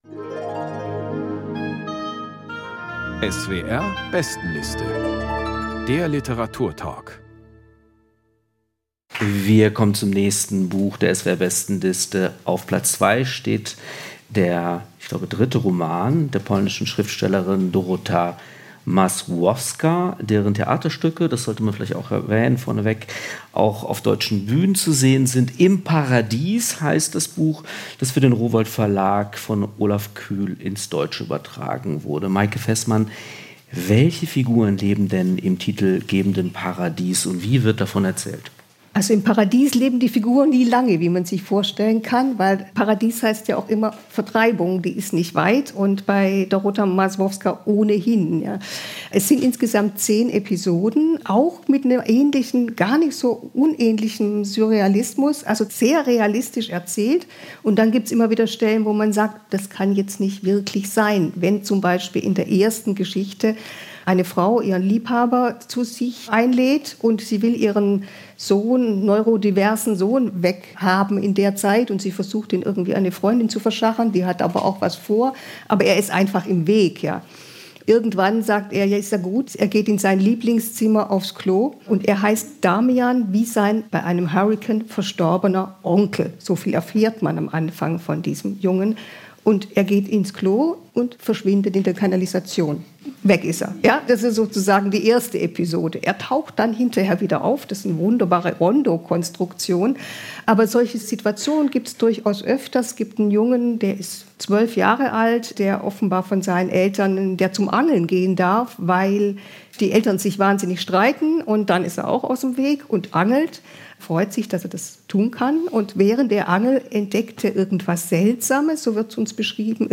Dorota Masłowska: Im Paradies | Lesung und Diskussion ~ SWR Kultur lesenswert - Literatur Podcast